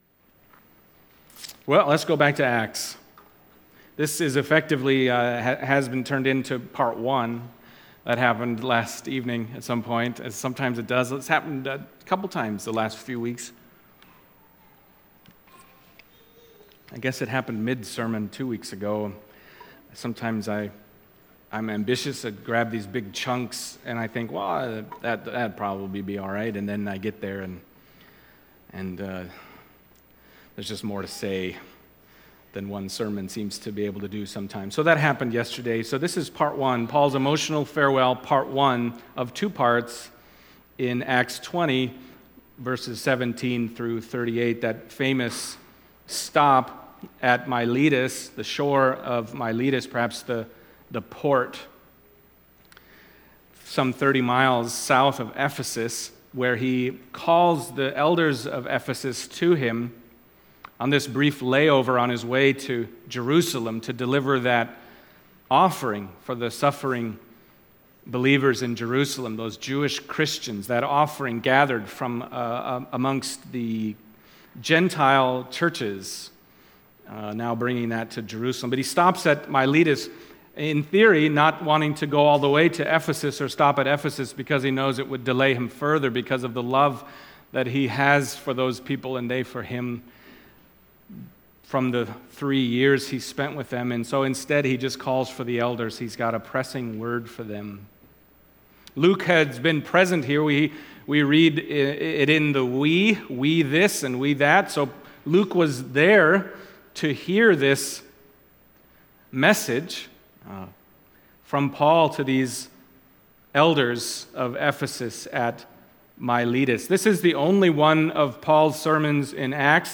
Acts 20:17-38 Service Type: Sunday Morning Acts 20:17-38 « Resurrection Comfort Paul’s Emotional Farewell